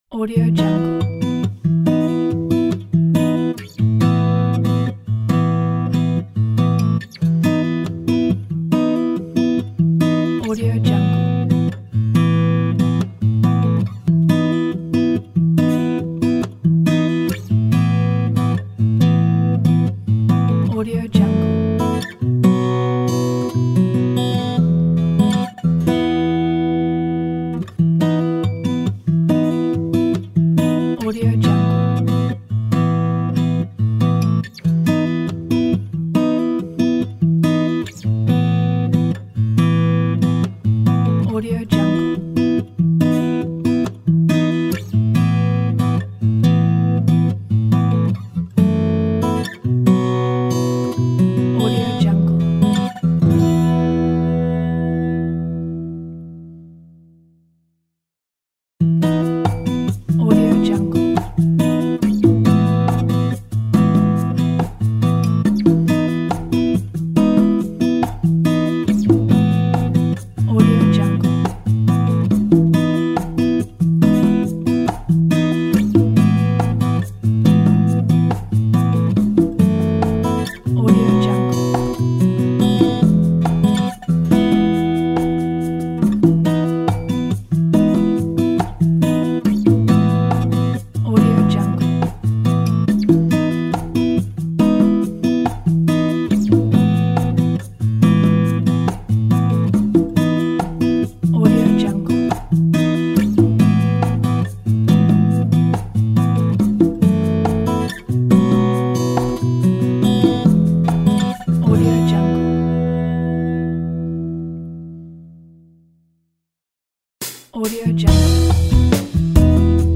辉煌感动